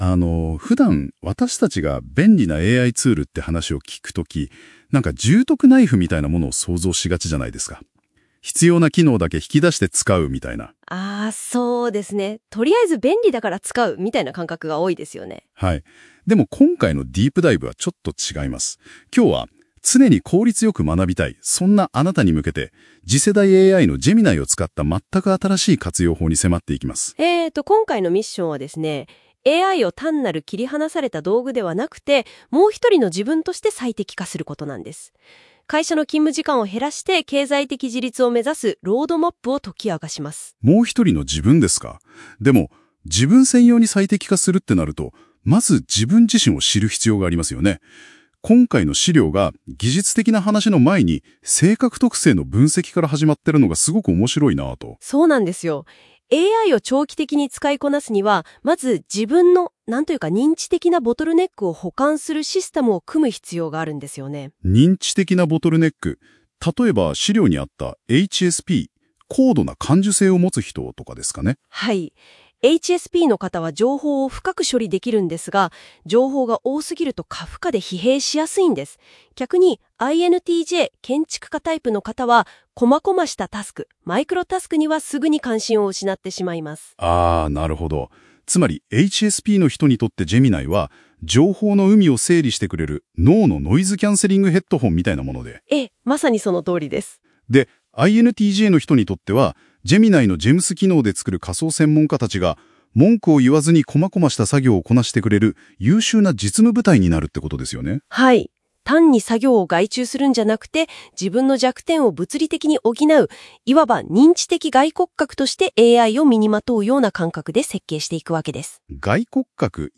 【音声解説】Geminiをもう一人の自分へ最適化する
音声解説を追加しました。